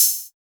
ANALOG OHH.wav